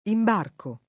imbarco [ imb # rko ], -chi